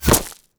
bullet_impact_gravel_02.wav